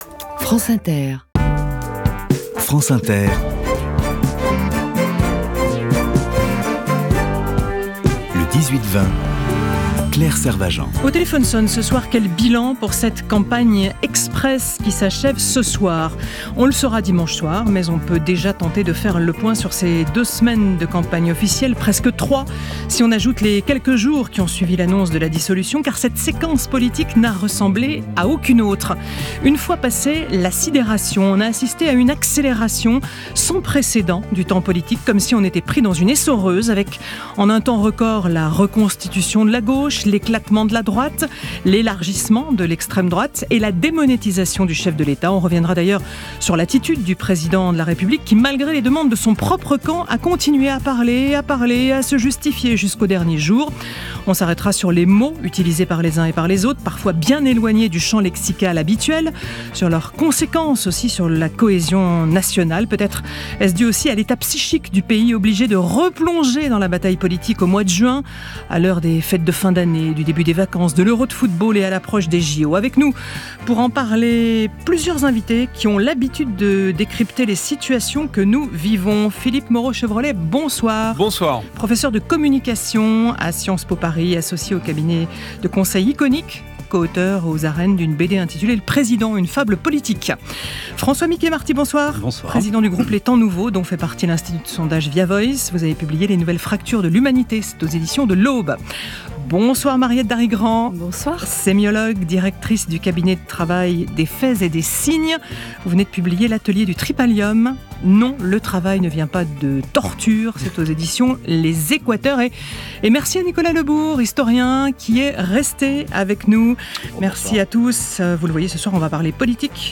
« Le Téléphone sonne » est une émission emblématique de France inter, sur les ondes depuis 1977. Son charme tient à ce que les intervenants n’ont aucune idée des propos que vont tenir les auditeurs à l’antenne, et enchaînent spontanément sur ces retours qui sont souvent intéressants quant à l’état du pays.